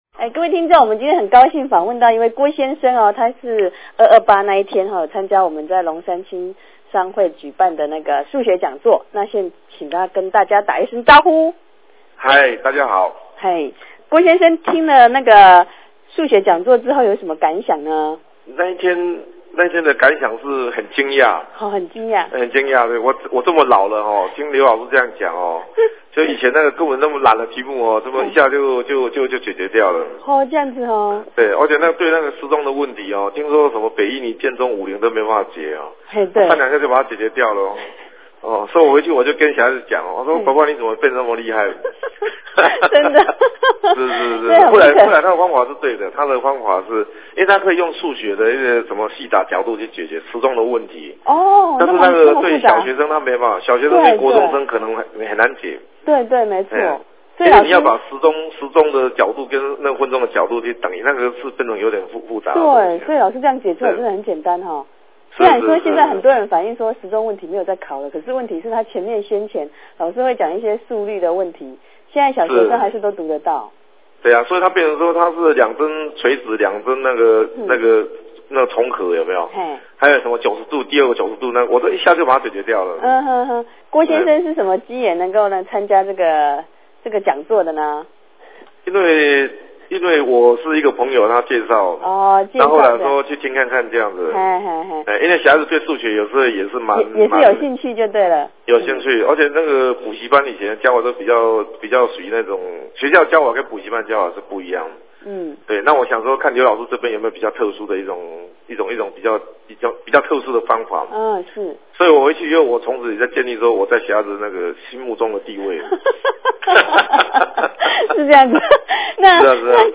聲音)：９８年２月２８日台北市龍山青商會數學講座。